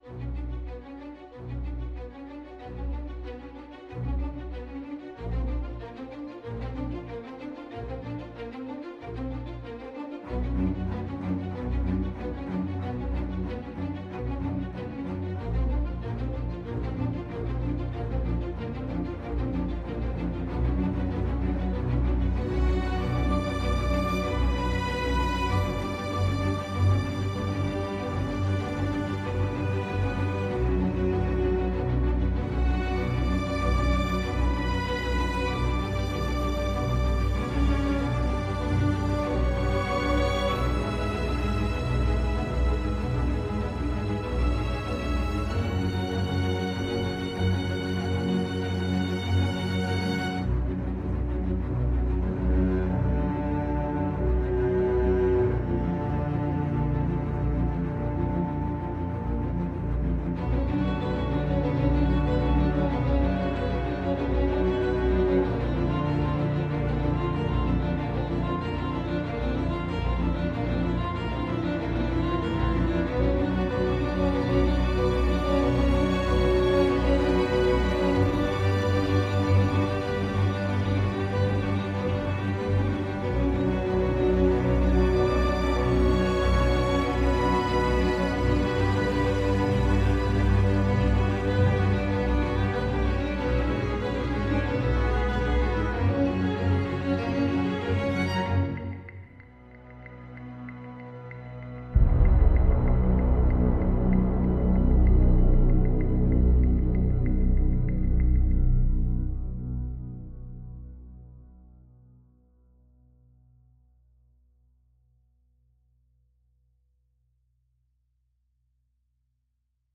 Entre mélancolie et gravité.